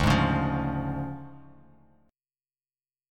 DmM7b5 Chord
Listen to DmM7b5 strummed